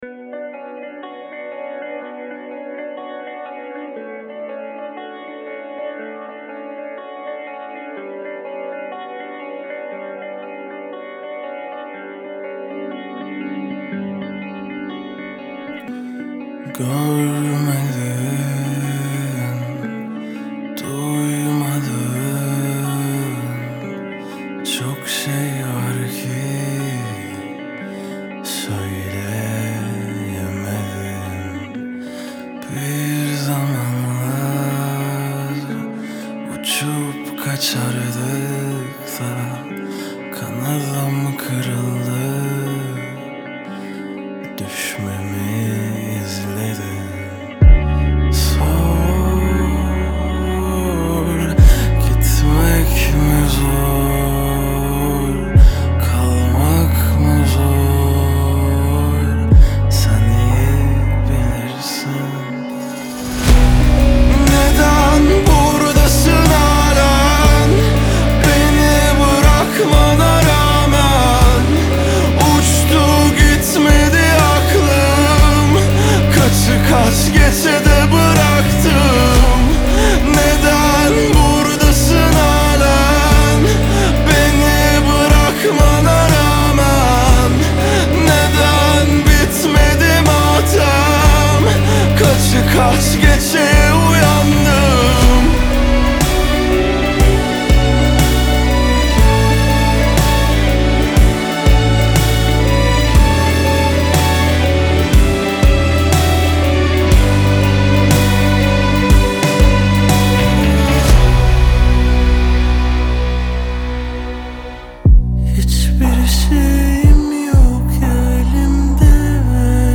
Трек размещён в разделе Турецкая музыка / Альтернатива.